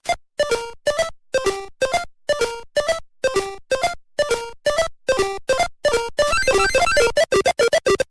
Platforms' timer jingle